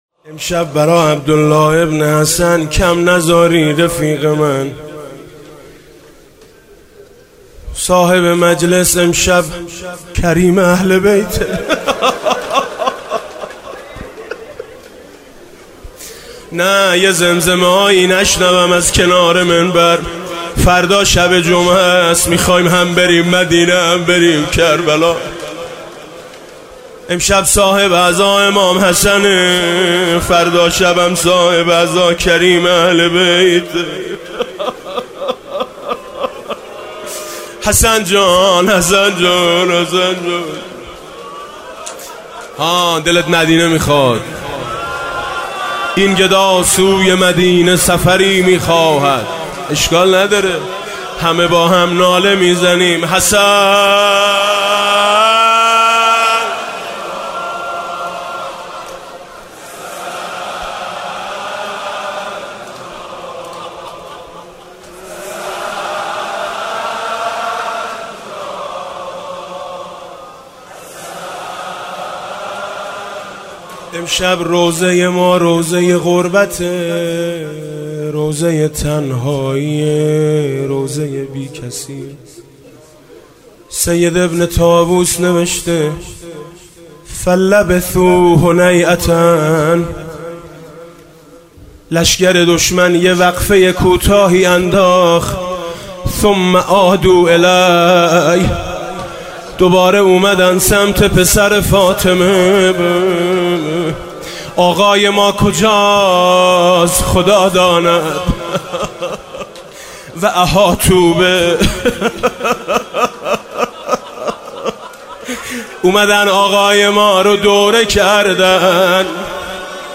روضه - عمو تنهات نمی ذارم